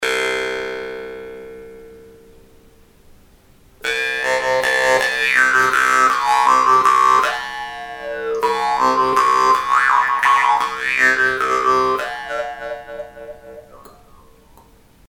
Diese geschmeidige tschechische Maultrommel besticht durch ihre gute Klangentwicklung, Haptik und Erscheinung.
Man spürt sie kaum an den Zähnen und ihr Klangbild ist besonders im Obertonbereich sehr melodiös.
Wir bieten die Soutok in der Stimmung C2 an.
brumle-soutok-maultrommel-c2.mp3